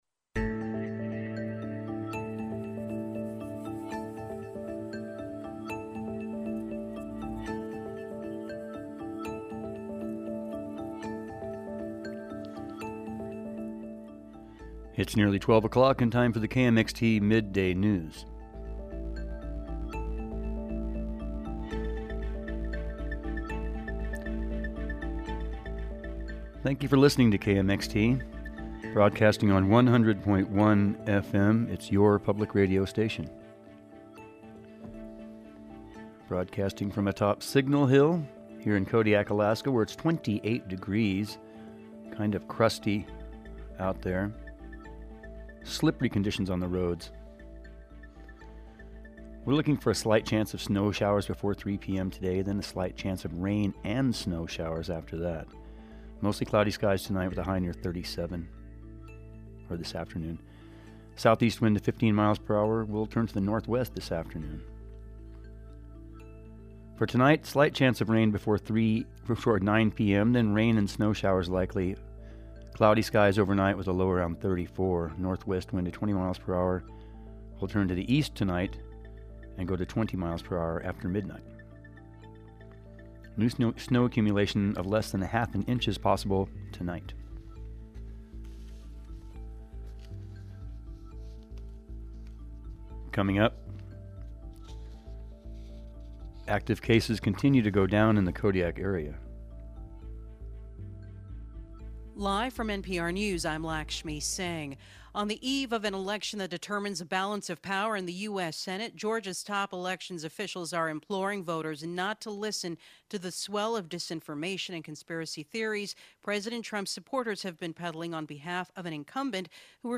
Noon Newscast – Monday, January 4, 2020
Nine new cases of Covid-19 reported in Kodiak. Minor earthquake in Kodiak over the weekend. First ever oil lease sale in the Arctic National Wildlife Refuge just two days away. All that and more on today’s midday news report.